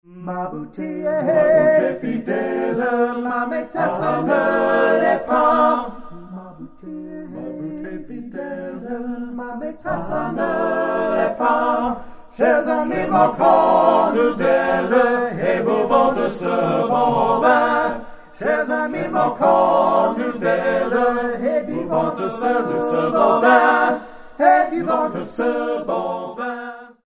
Sung a capella